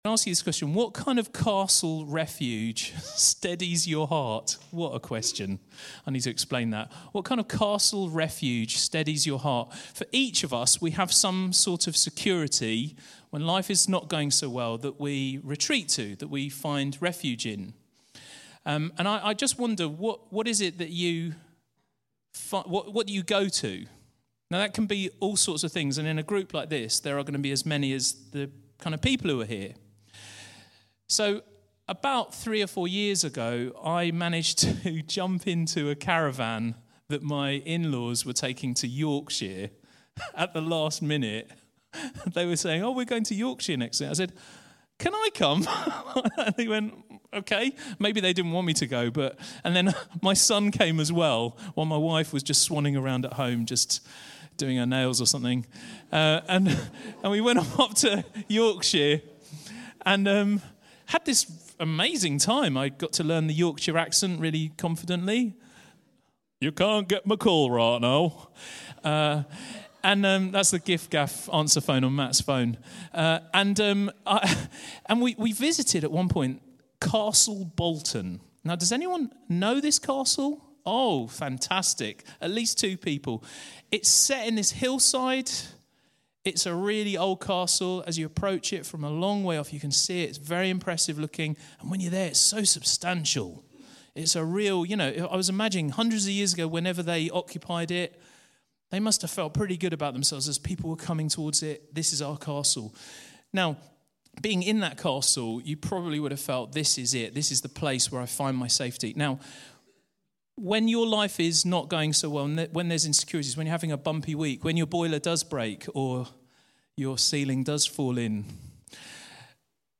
Talk from Sunday 22 January 2023.